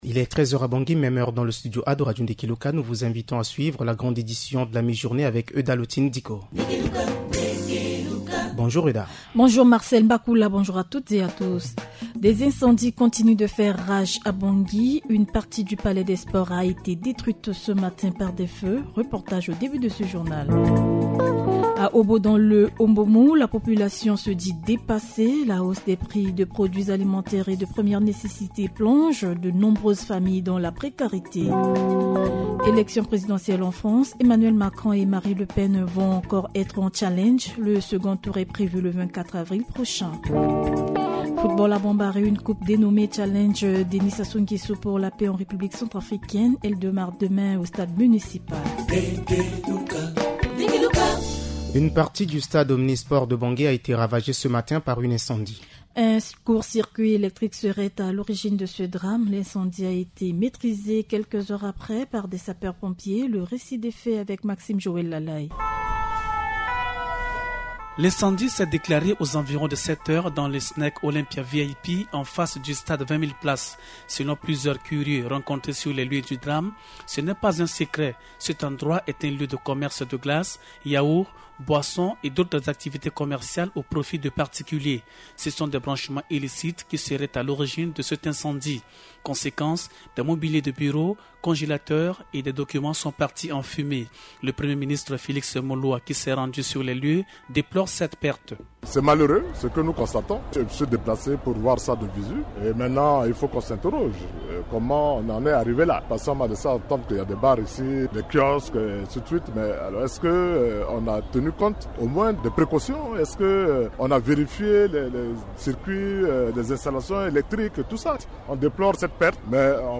Journal en français